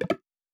Buzz Error (9).wav